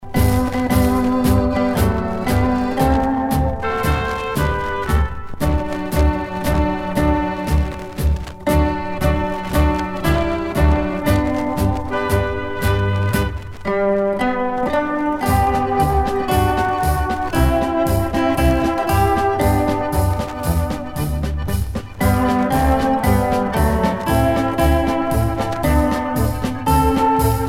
danse : marche
Pièce musicale éditée